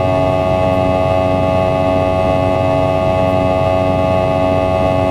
v2500-buzz.wav